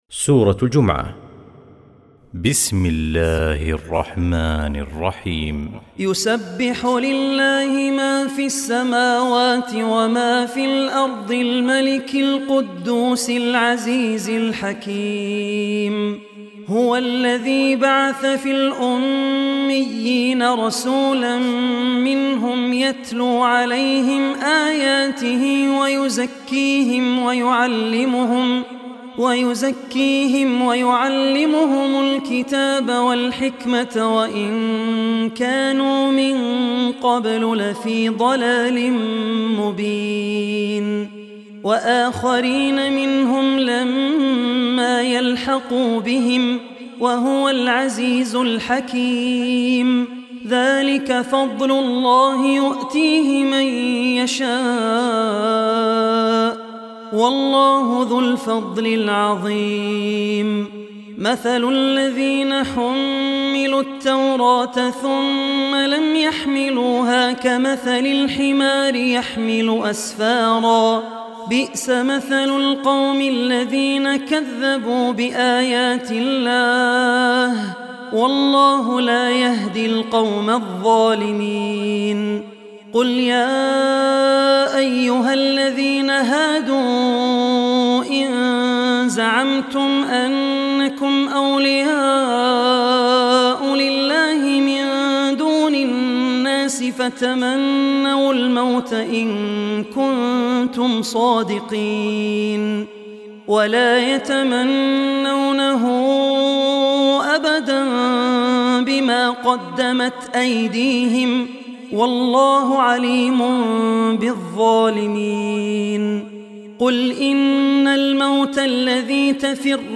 Surah Jummah Online Recitation by Al Ossi
Surah Jummah, listen online mp3 tilawat / recitation in the voice of Abdul Rehman Al Ossi.